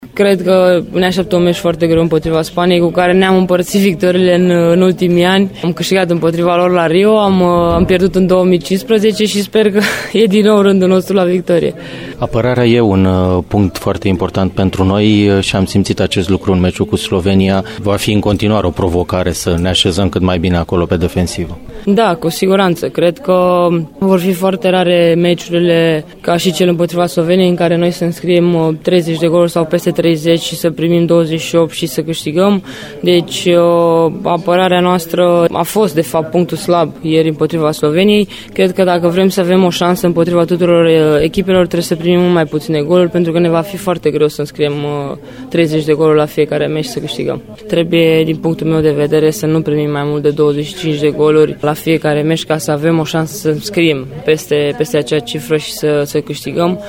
Meciul cu Spania a fost prefaţat şi de liderul reprezentativei noastre, Cristina Neagu:
Neagu-despre-jocul-cu-Spania.mp3